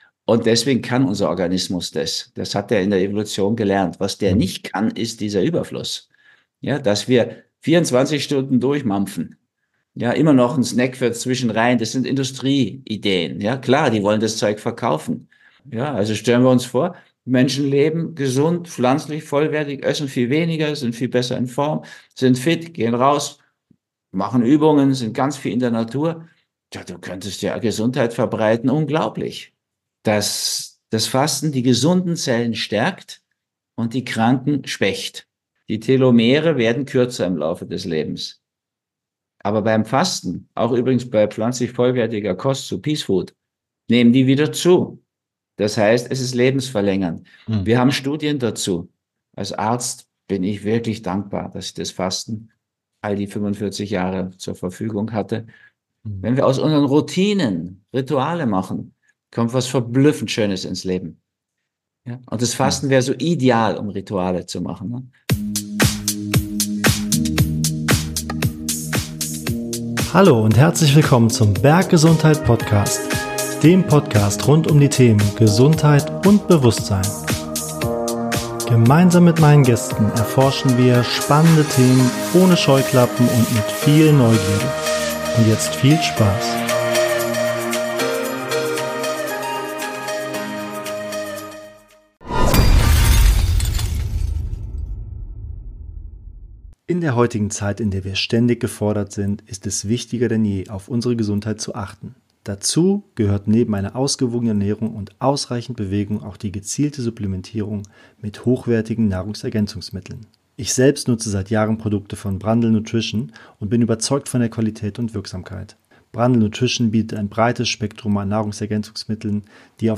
Beschreibung vor 2 Jahren In diesem informativen Gespräch erzählt der Bestsellerautor Rüdiger Dahlke von seinen Erfahrungen aus über 40 Jahren Fastenbegleitung. Warum wir überhaupt Fasten können, wie wir mit dem Fasten beginnen sollten und welche nachhaltigen Gesundheitsvorteile Intervallfasten, Langzeitfasten aber auch die vegane, vollwertige Ernährung haben klären wir in dieser prall gefüllten, spannenden Podcast-Episode.